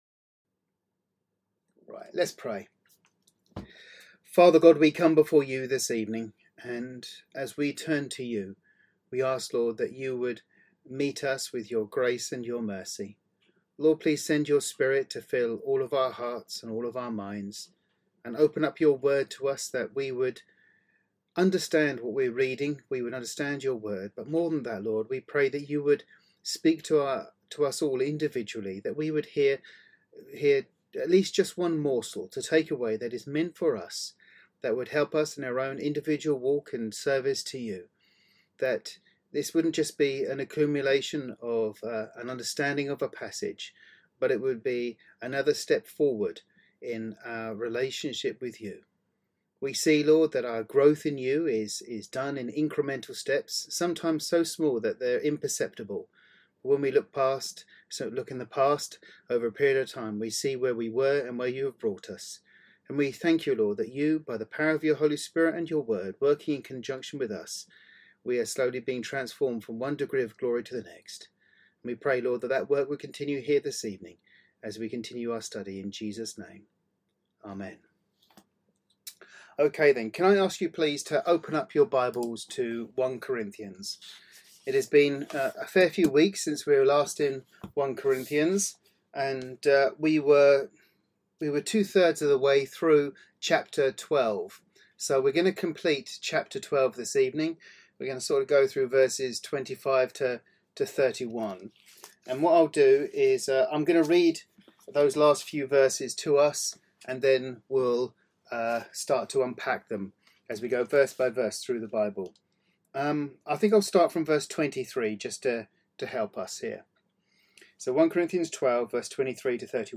The twenty-third sermon in a series